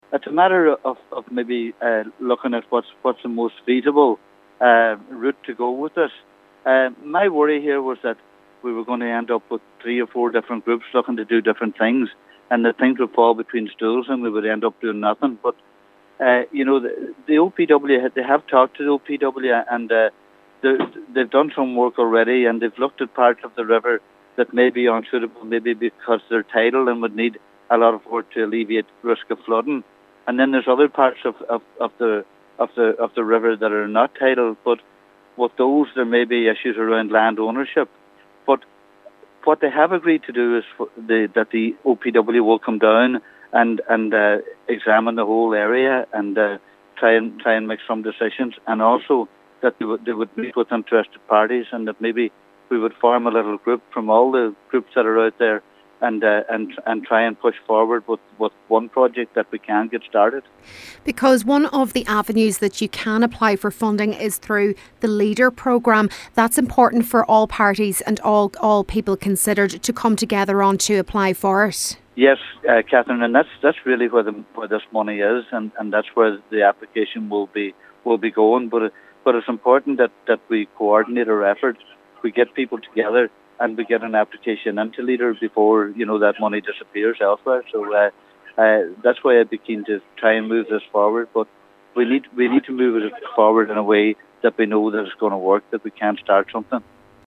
Mayor of Letterkenny Cllr. Jimmy Kavanagh says all interested parties need to come together to acheive the best possible outcome: